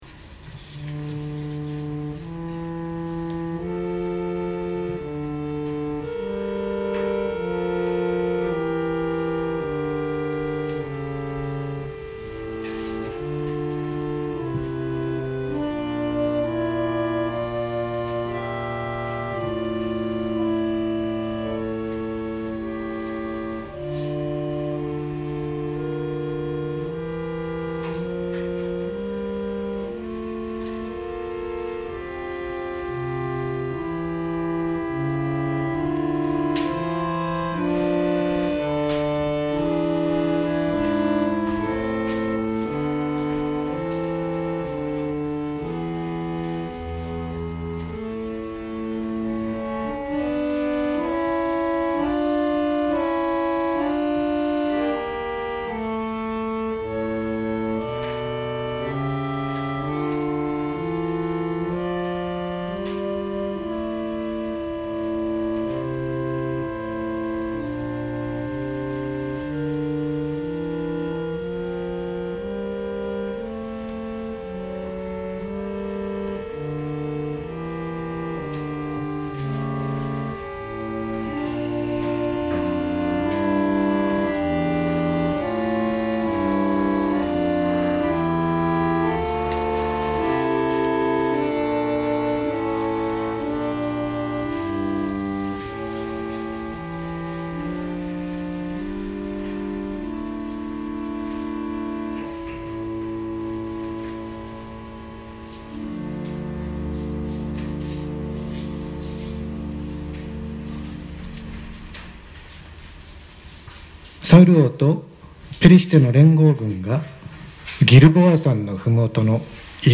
2020年03月29日朝の礼拝「アマレク追撃 아말렉 추격」せんげん台教会
説教アーカイブ。